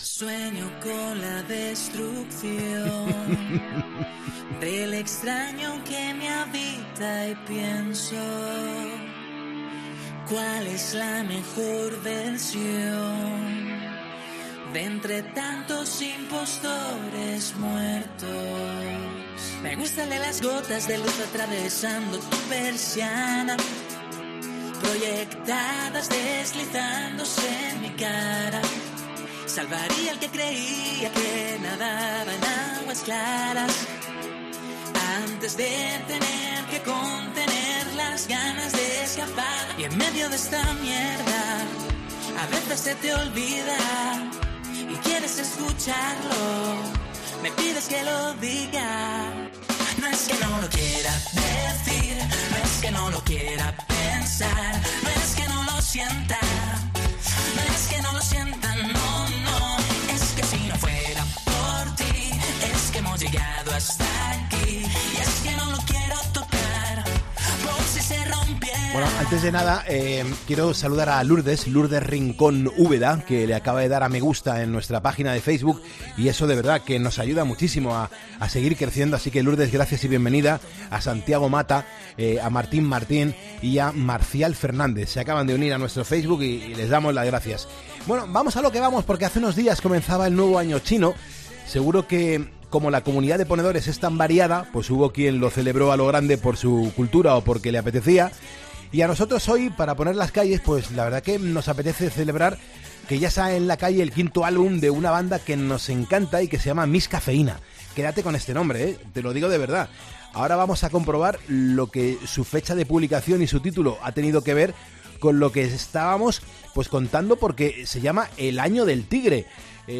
Entrevista a Alberto Jiménez, la voz de Miss Caffeina